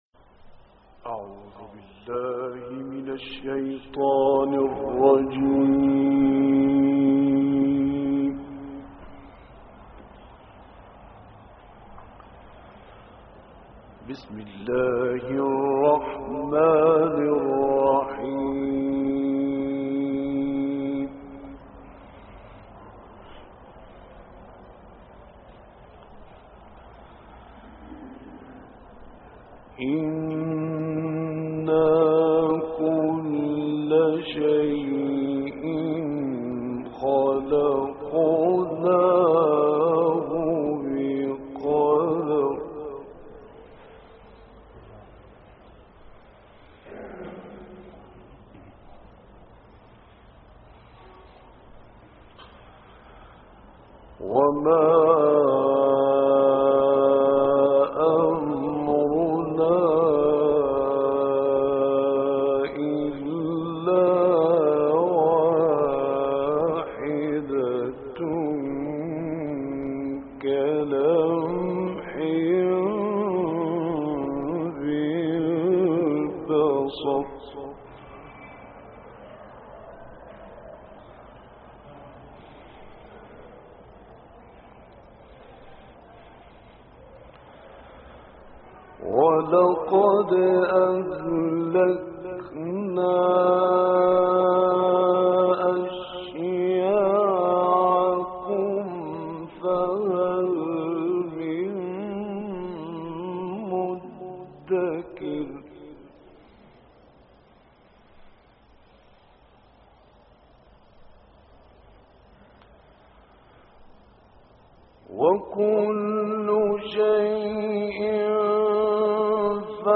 دانلود قرائت سوره های غافر آیات 49 تا آخر ، رحمان 1 تا 33 و تکاثر - استاد راغب مصطفی غلوش